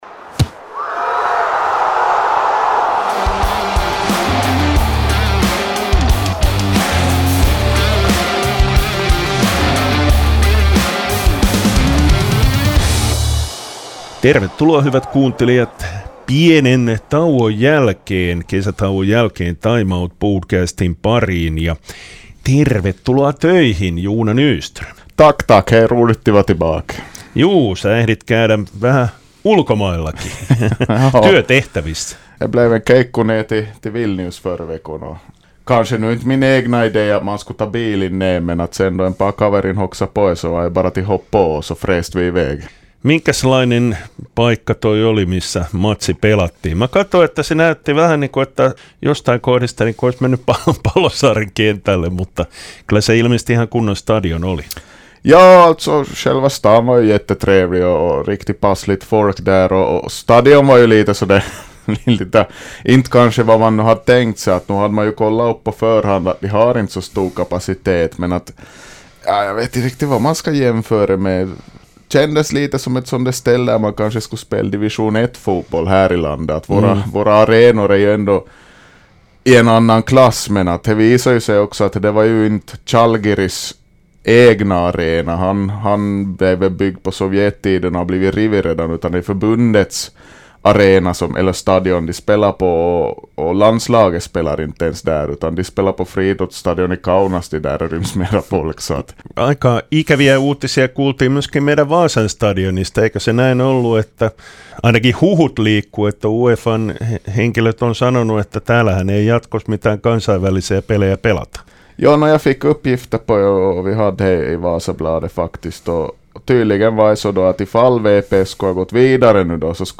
Studiossa tuttuun tapaan kaksikielistä ohjelmaa juontamassa